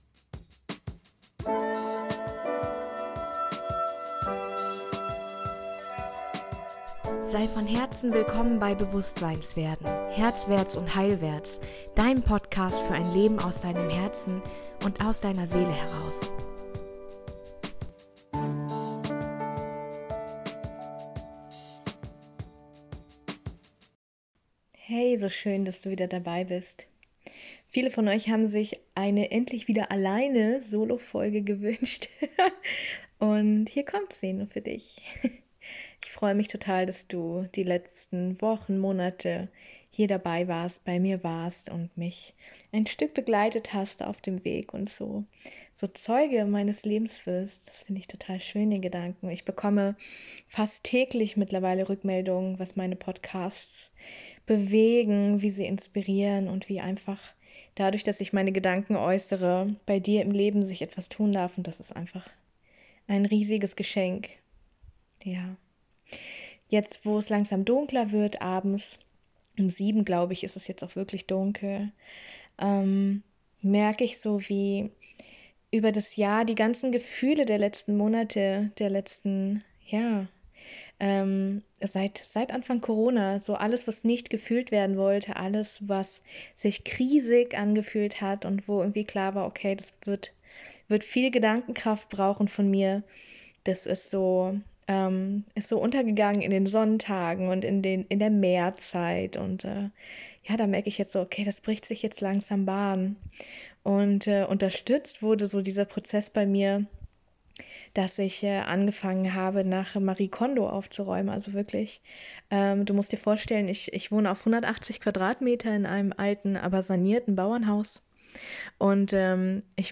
Beschreibung vor 4 Jahren In dieser Folge teile ich mit dir meinen Geheimtipp für intensive Zeiten. Er ist sofort umsetzbar für jede und jeden von uns, und ich freue mich, euch damit mal wieder eine Solo-Folge bieten zu können.